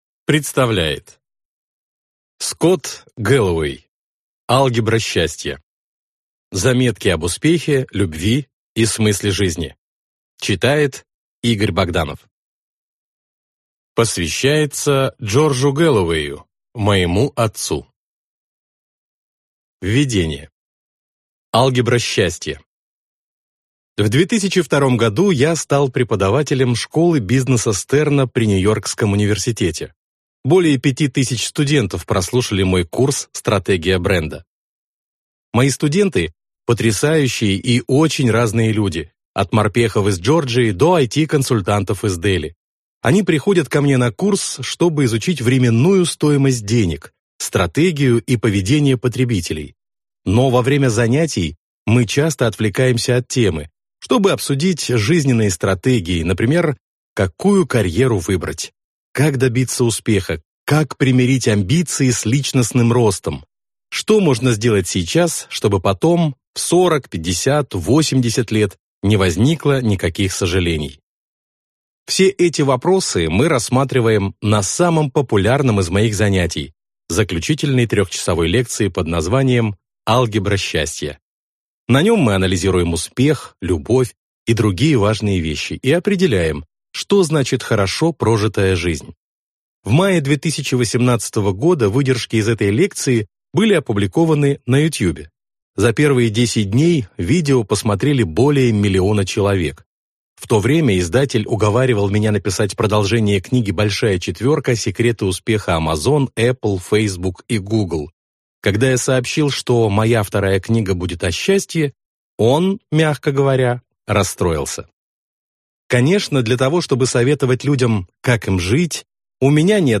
Аудиокнига Алгебра счастья | Библиотека аудиокниг